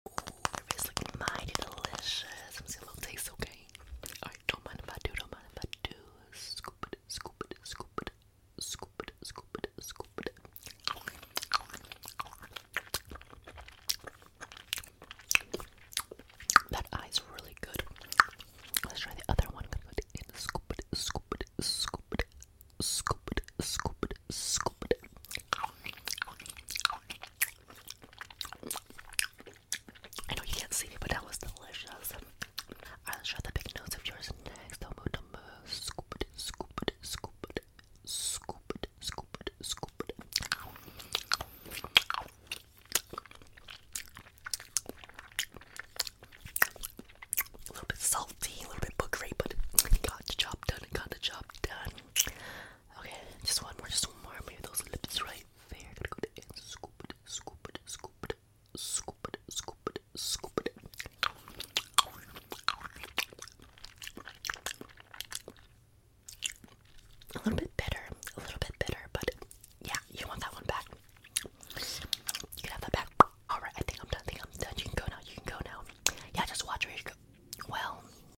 Wooden spoon scooping face asmr sound effects free download